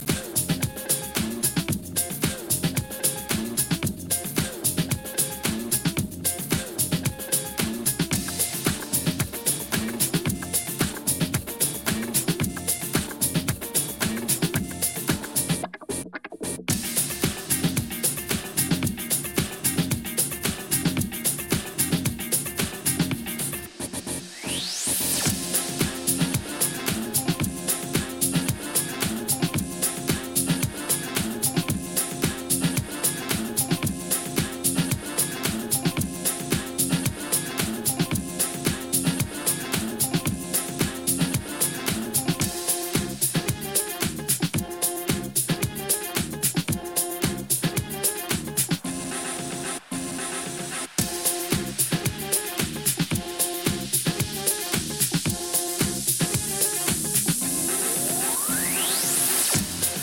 lifts the tempo
slams hard